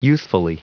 Prononciation du mot youthfully en anglais (fichier audio)
Prononciation du mot : youthfully
youthfully.wav